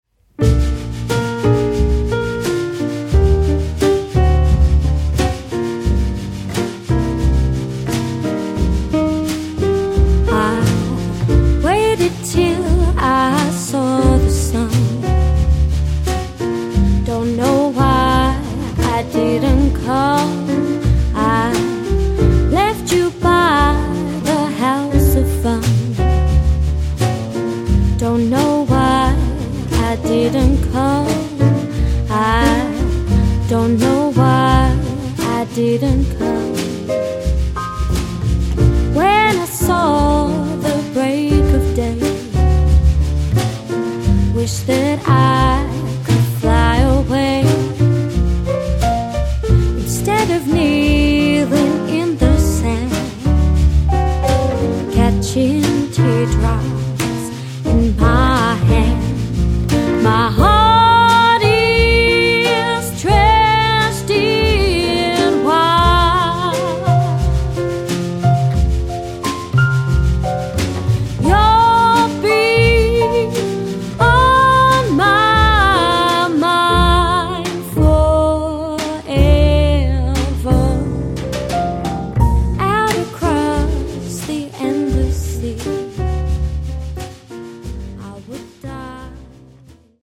a sophisticated set of swinging jazz classics
• Sophisticated vocal-led jazz band